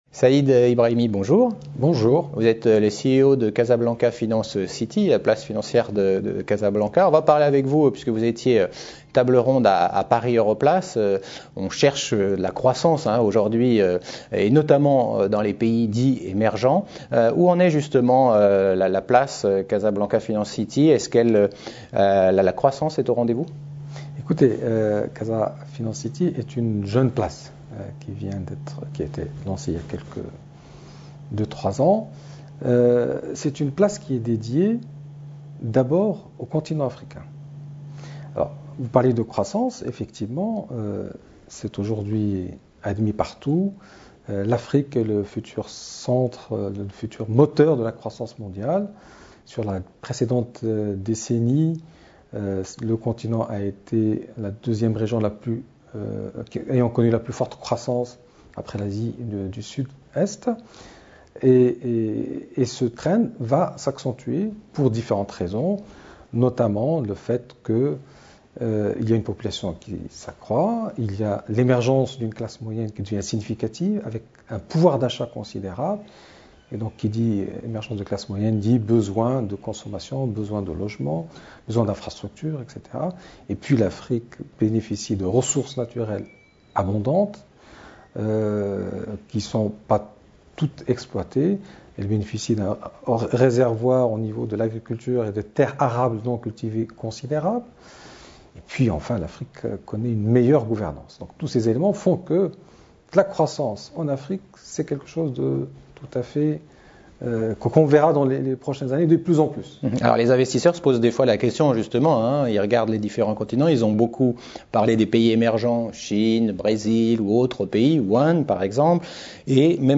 Lors des Rencontres Paris Europlace 2013 qui avaient pour thème cette année « Growth and Investment Opportunities in Europe », nous avons interviewé des personnalités qui participaient à ces échanges internationaux.